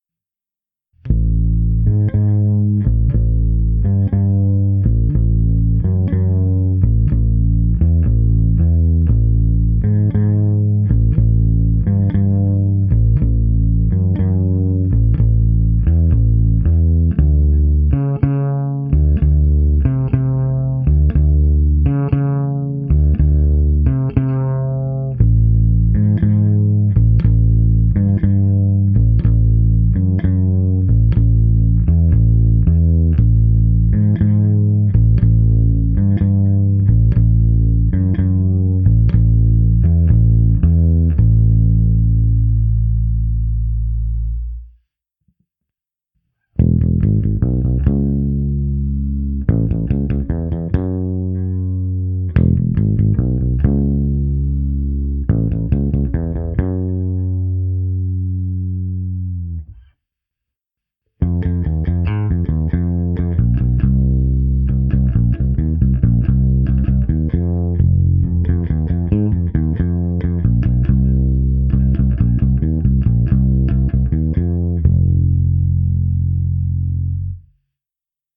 Baskytaru jsem dostal s nylonovými tapewound strunami nejspíše od firmy La Bella.
Pro alespoň rámcovou představu, jak baskytara zní přes reálný aparát, jsem předchozí nahrávku protáhl simulačním softwarem AmpliTube.
Ukázka mého zvuku 2 přes AmpliTube